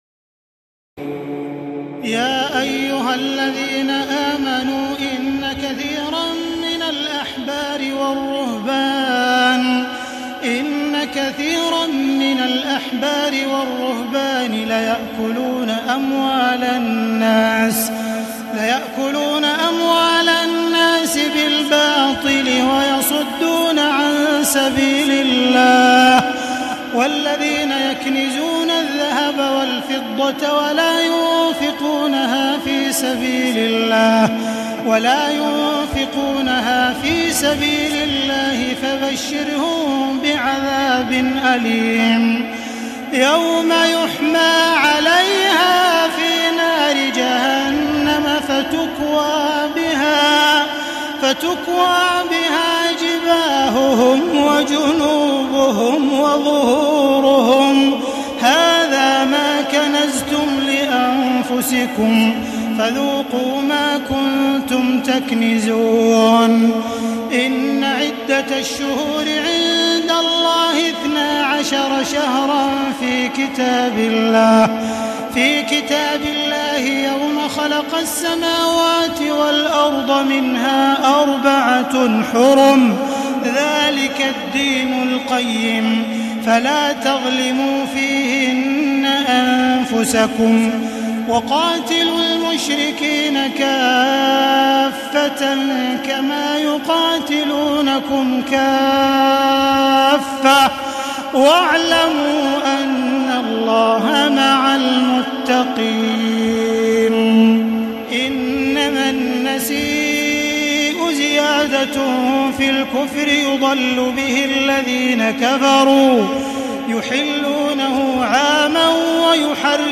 تراويح الليلة العاشرة رمضان 1435هـ من سورة التوبة (34-93) Taraweeh 10 st night Ramadan 1435H from Surah At-Tawba > تراويح الحرم المكي عام 1435 🕋 > التراويح - تلاوات الحرمين